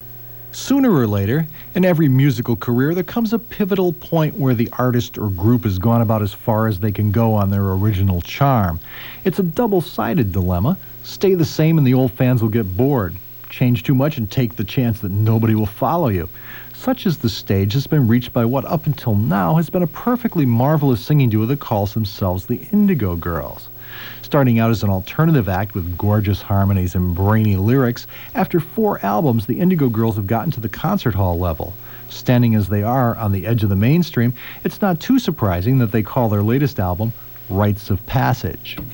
lifeblood: bootlegs: 1992-xx-xx: wbfo - buffalo, new york
01. reviewer (0:38)